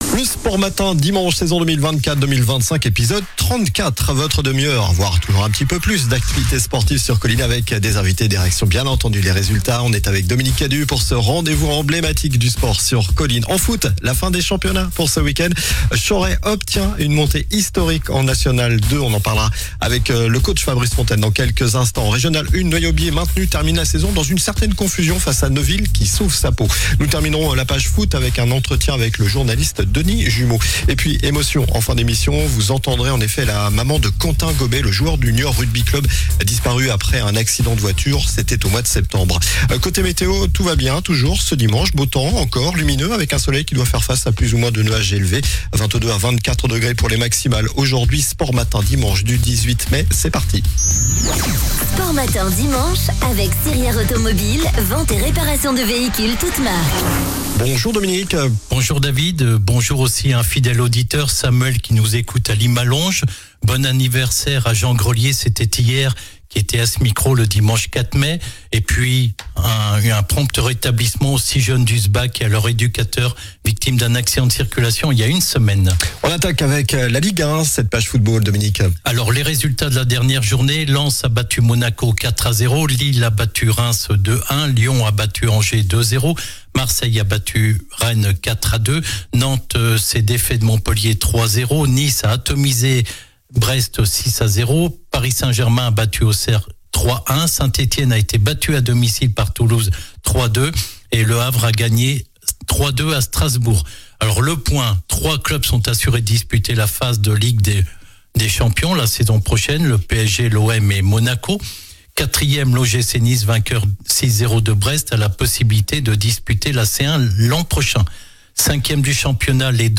Nous terminerons la page football par un entretien